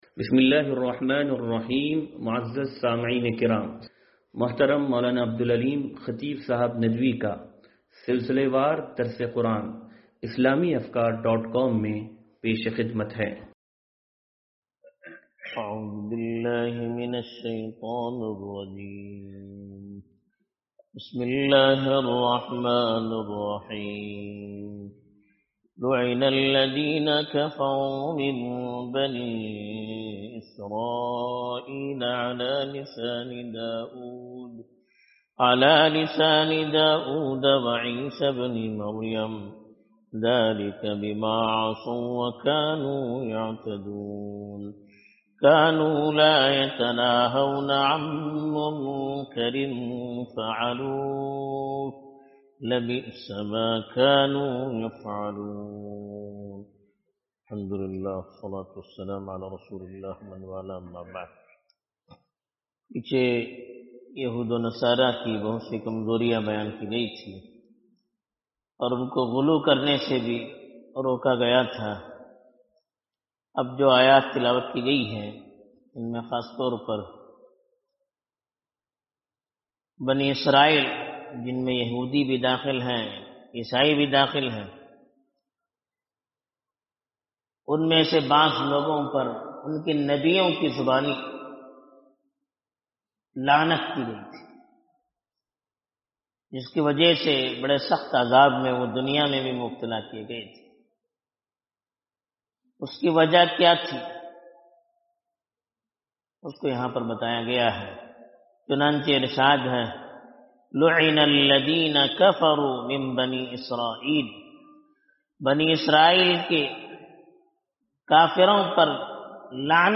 درس قرآن نمبر 0475